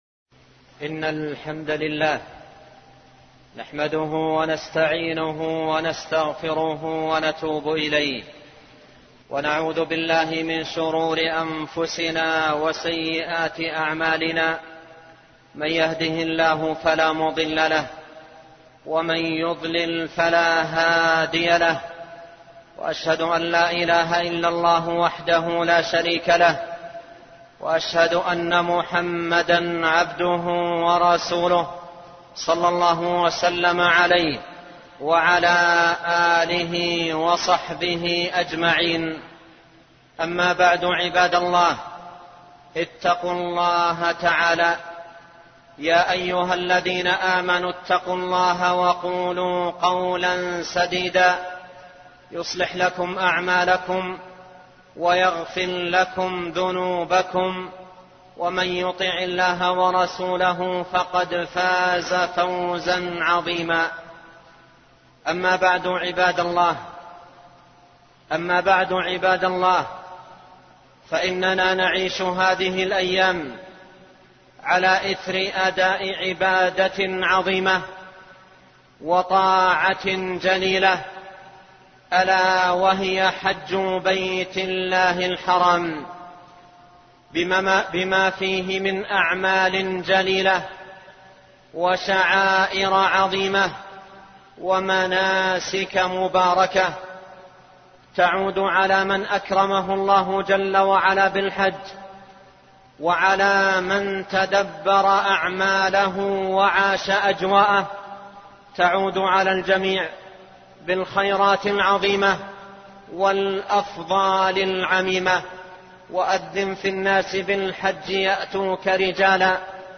خطب العبادة